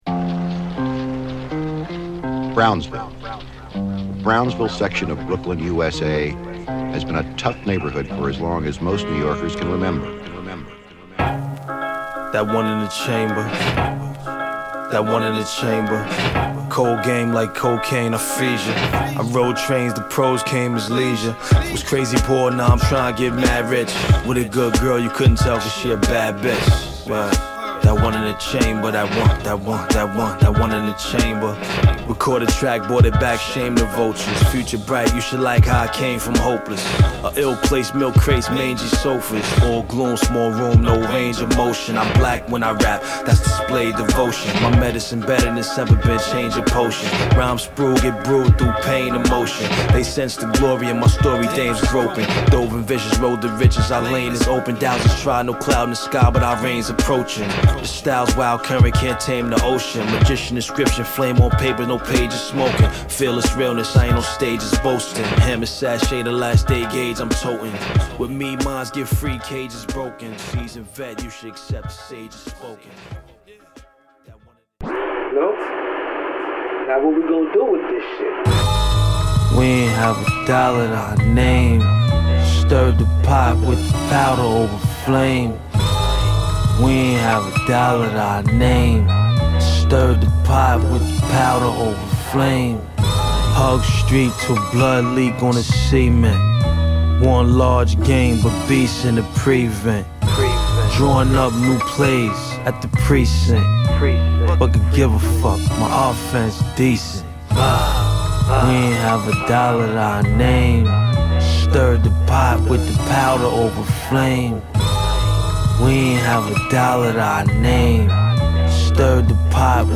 アルバム通してブレの無いドープなループとドープなラップが炸裂！